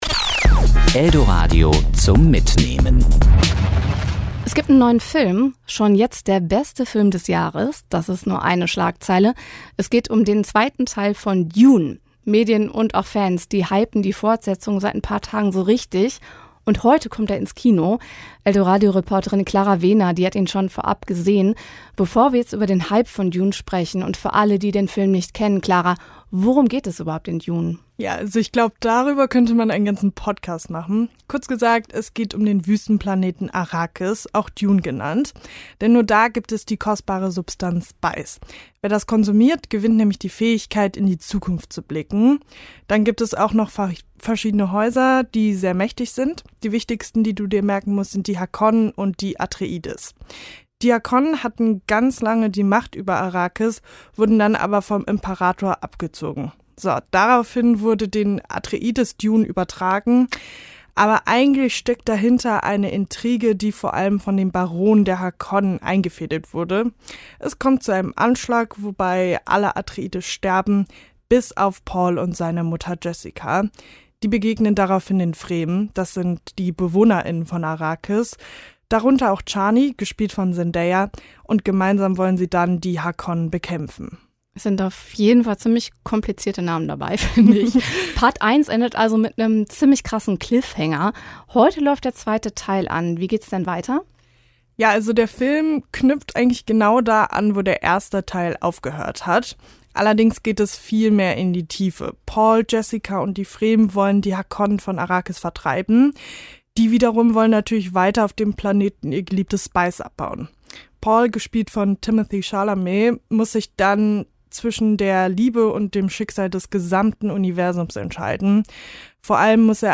Kino-Rezension: Dune Part 2